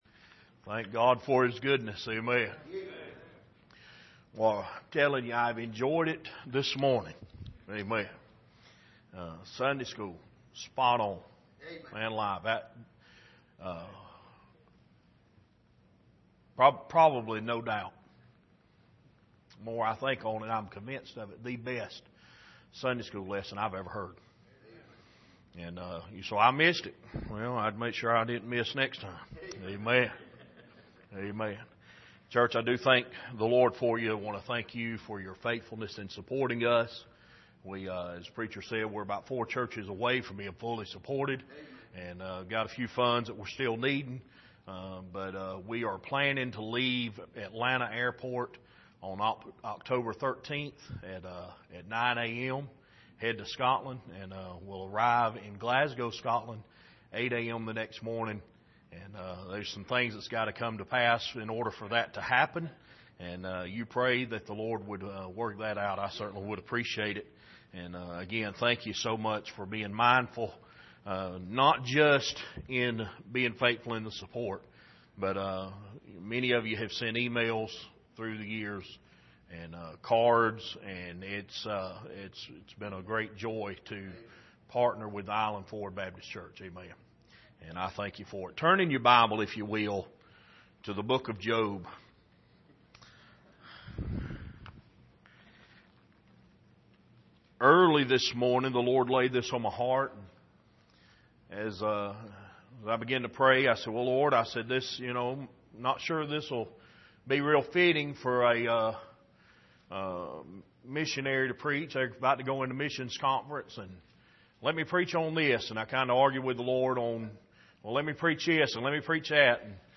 Job 6:2-3 Service: Sunday Morning Dealing With Depression « The Invitation To Pray